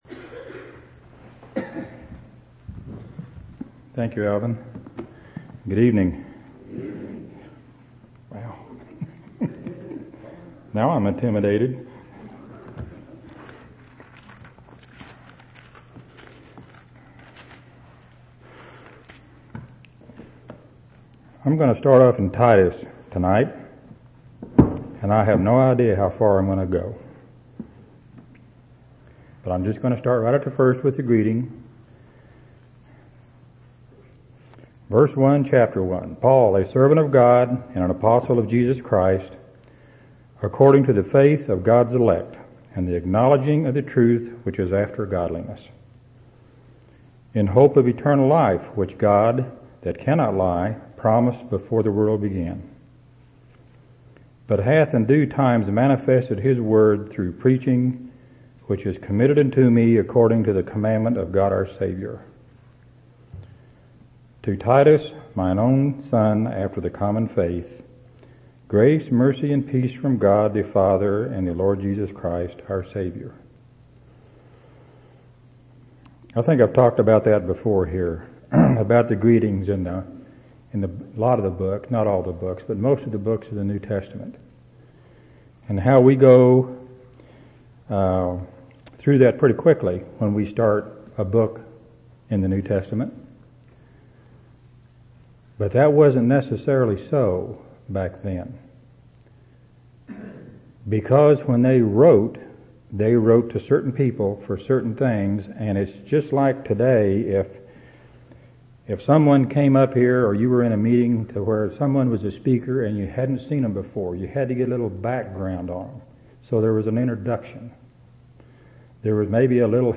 1/30/2005 Location: Temple Lot Local Event